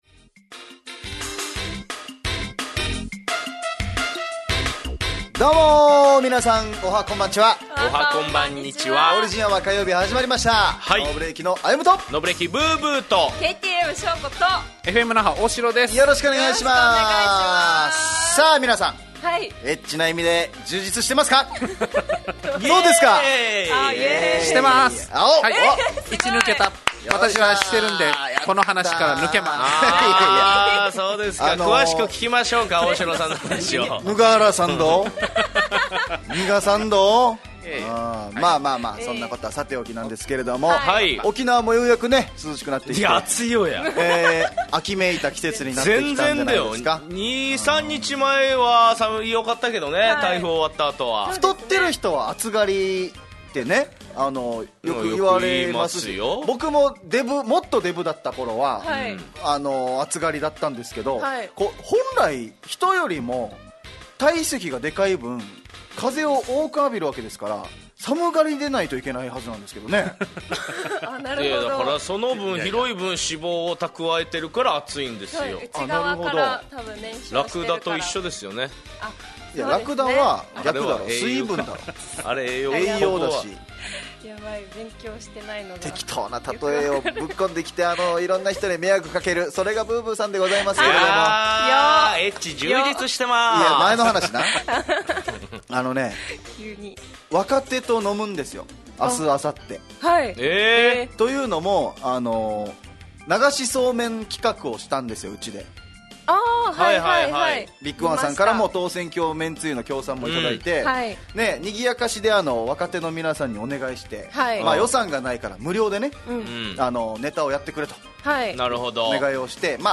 fm那覇がお届けする沖縄のお笑い集団・オリジンメンバー出演のバラエティ番組